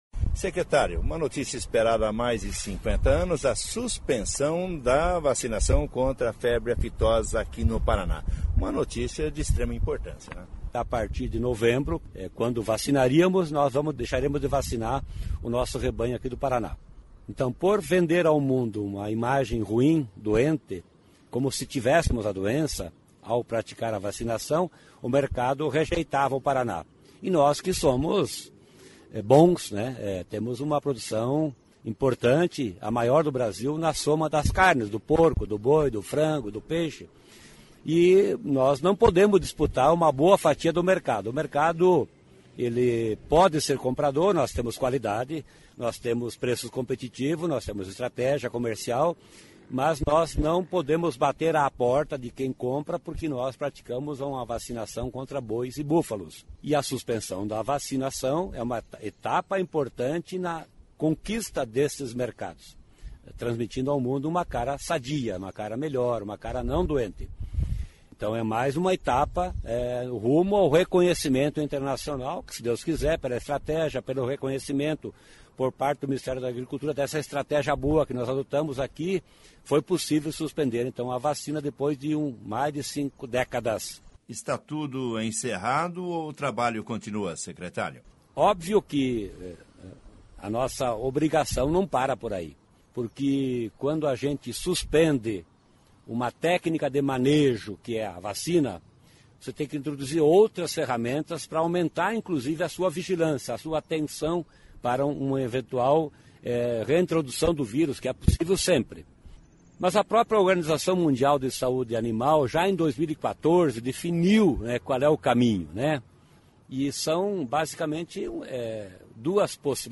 Entrevista com o Secretário de Agricultura e do Abastecimento, Norberto Ortigara, sobre a suspensão da vacinação contra a febre aftosa no Paraná